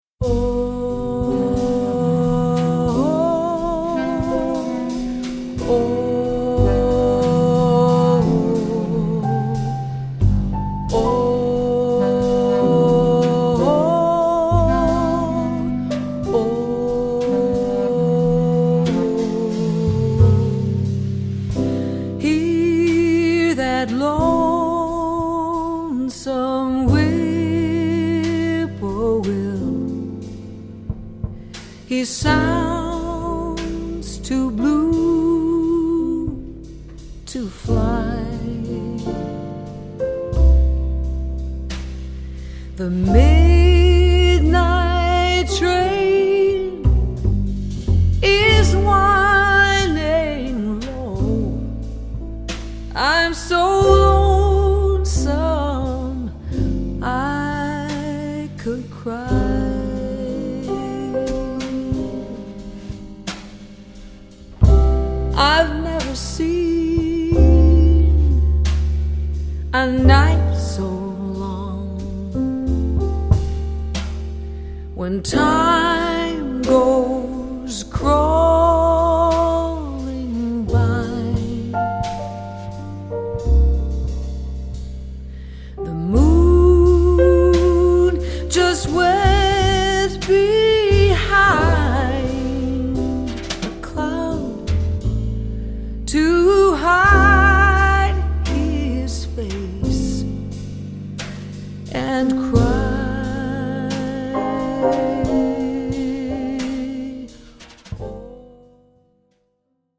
-   爵士及藍調 (293)
★ 明亮愉悅、充滿情感，最溫柔撫慰人心的樂章！
★ 頂級錄音打造清澈立體、圓潤滑順的動人歌聲！